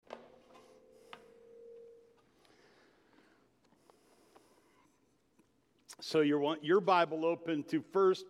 Sermons 1.